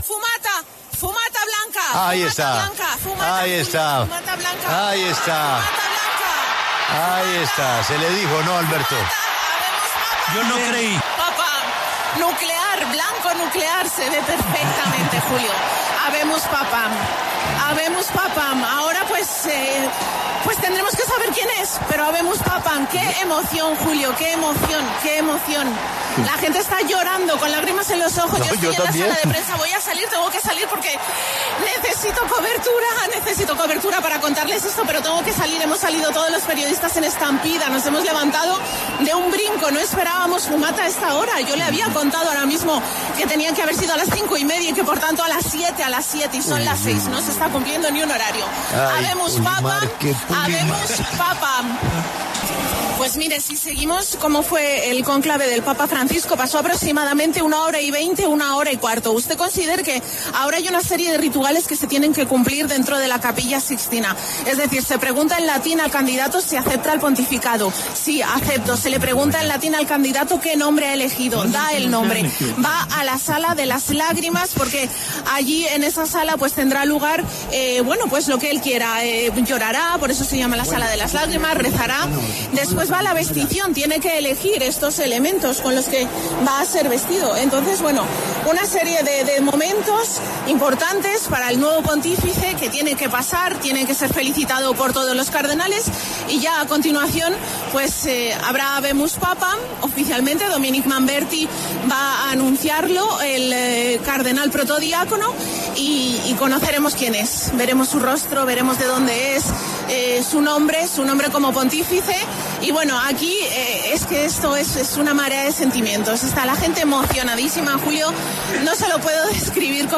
Los momentos de emoción quedaron registrados en vivo en la señal de La W, donde una gigantesca ovación recibió la noticia que confirmaba la elección de un nuevo sumo pontífice de la Iglesia católica.